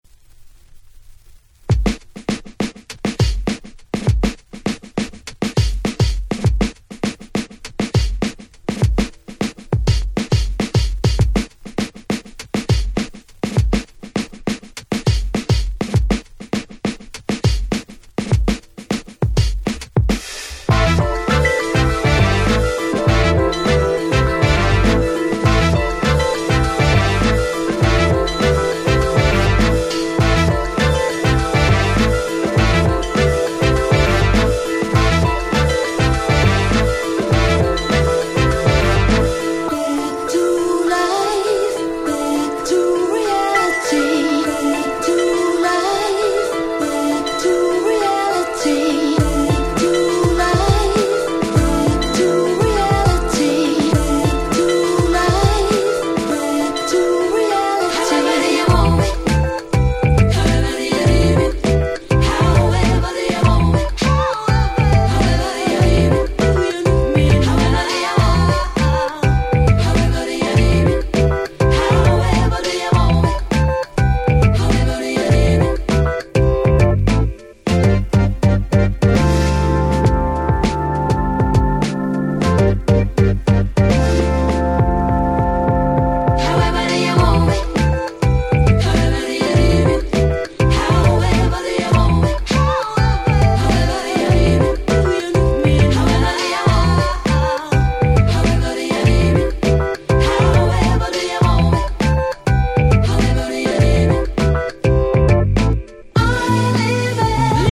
※試聴ファイルは他の盤より録音してございます。
23’ Super Nice Remix !!